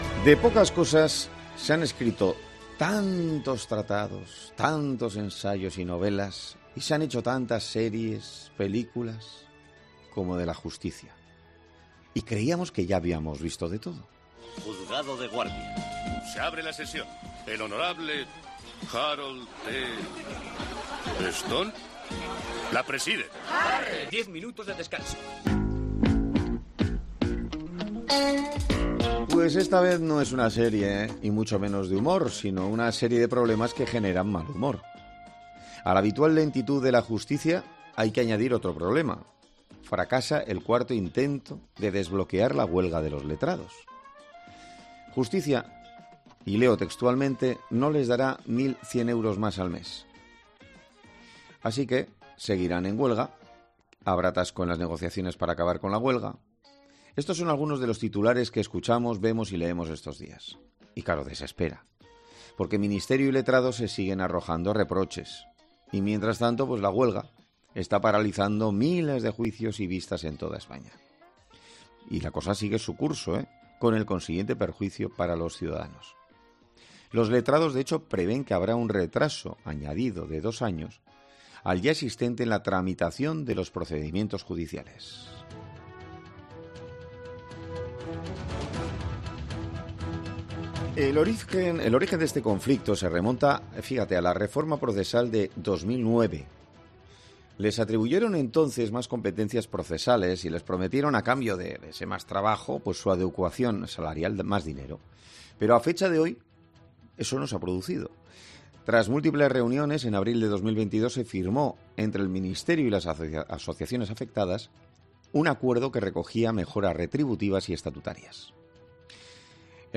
Uno de los letrados judiciales ha hablado en 'Herrera en COPE' acerca de quiénes son y las funciones que desempeñan en la Administración